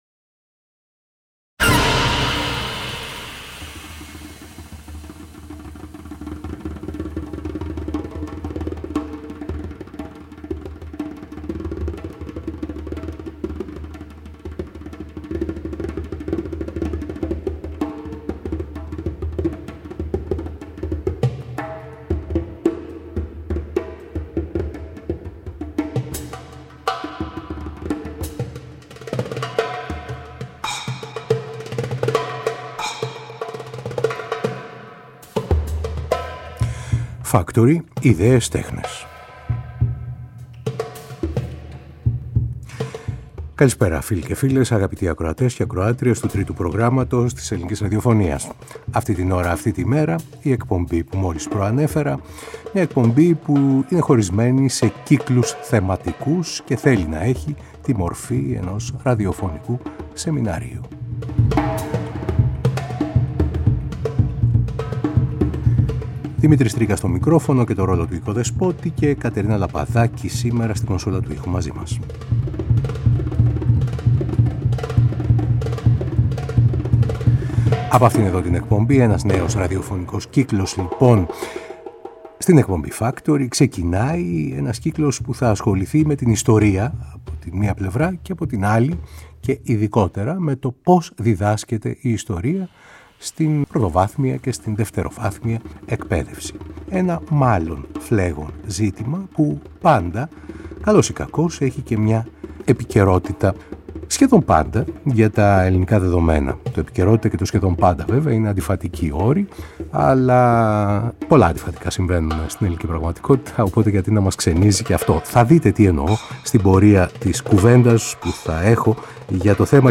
Μικρό διάλλειμα από τη σειρά για τη σκέψη του 20ου αι. και επιστροφή στο πεδίο της ιστορίας με έναν κύκλο ραδιοφωνικών σεμιναρίων για την ΙΣΤΟΡΙΑ και την ΔΙΔΑΣΚΑΛΙΑ της στην Εκπαίδευση, σε τρία επεισόδια.